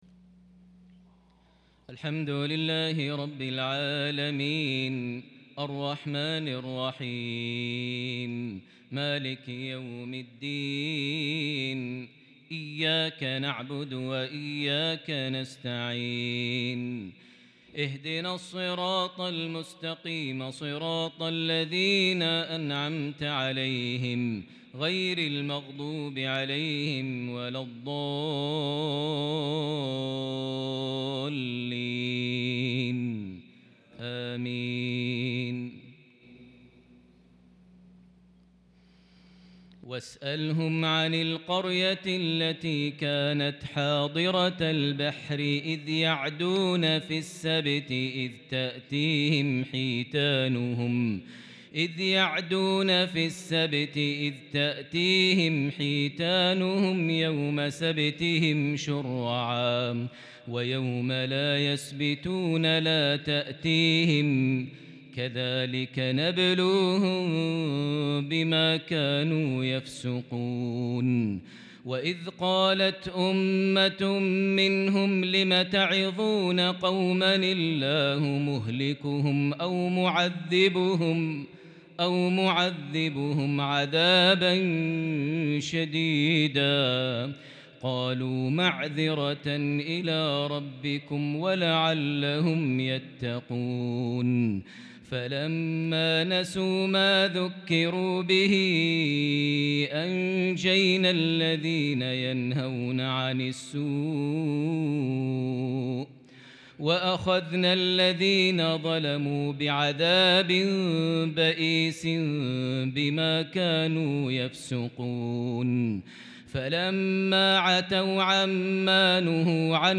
lsha 9-9-2021 prayer from Surah Al-Araf 163-174 > 1443 H > Prayers - Maher Almuaiqly Recitations